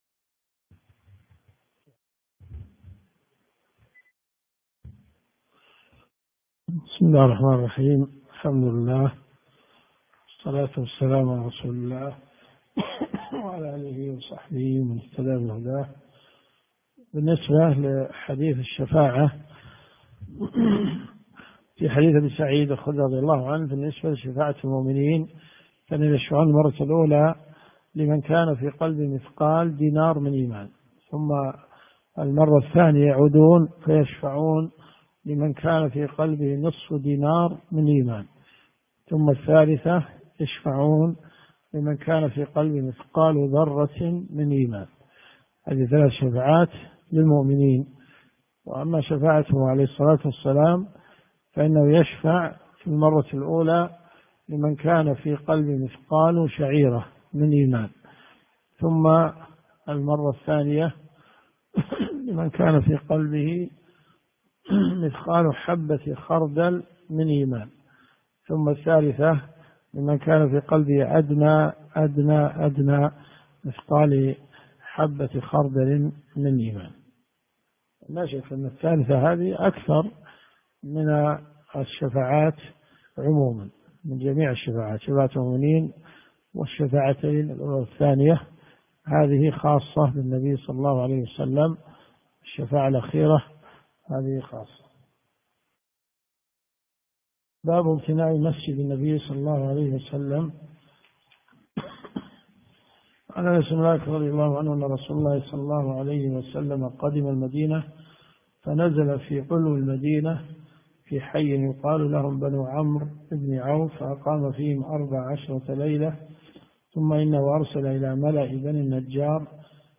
الدرس في الدقيقة 1.40 .